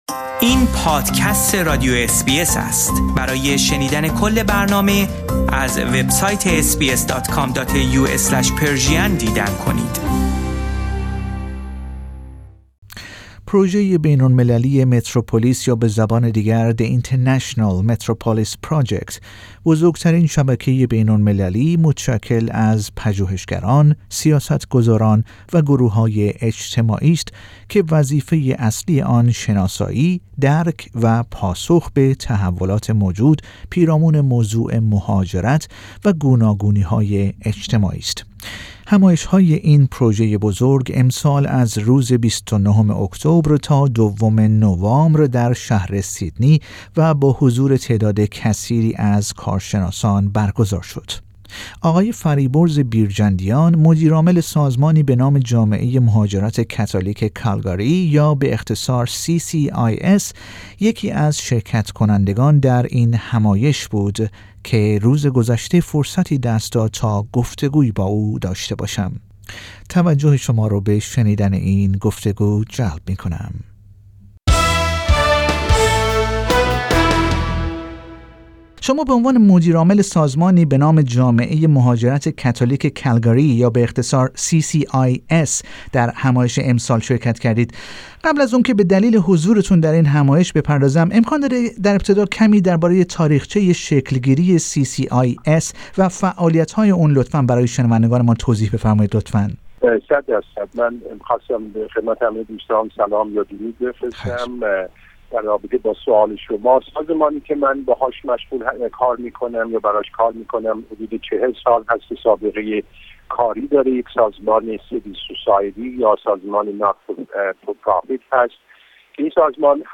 در گفتگو با برنامه فارسی رادیو اس بی اس